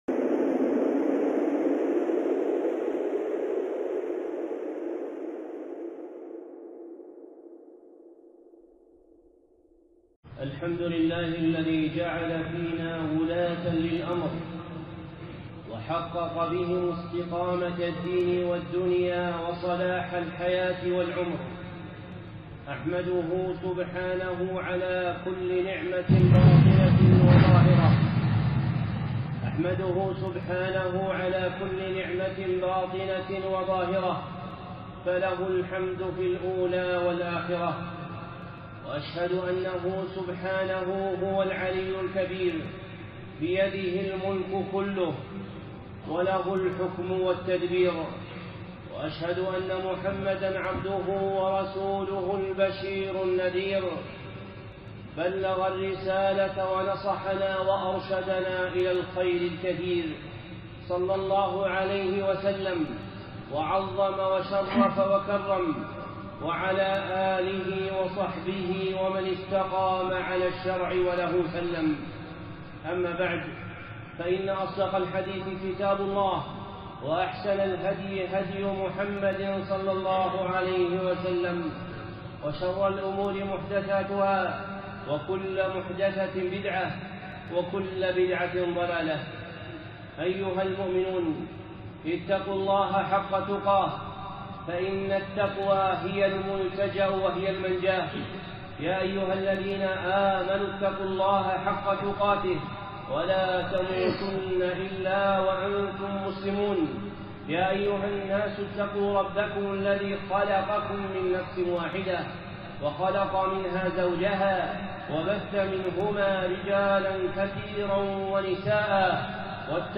خطبة
الخطب المنبرية